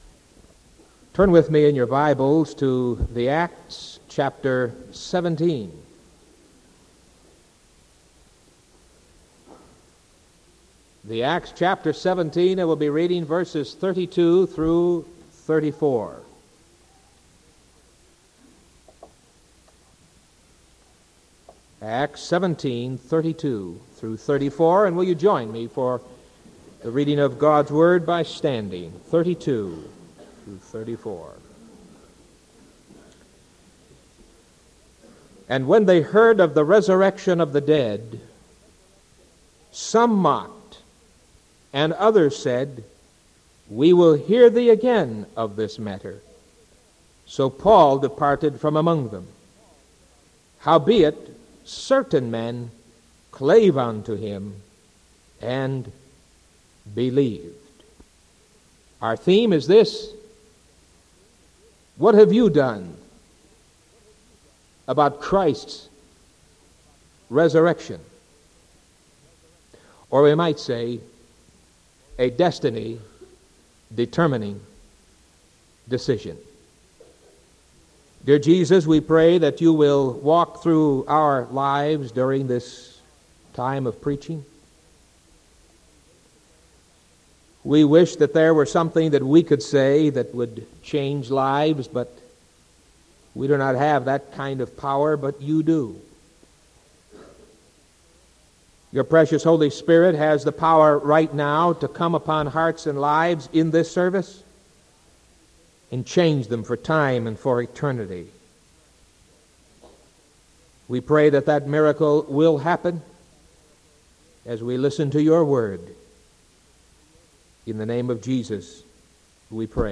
Sermon March 26th 1978 AM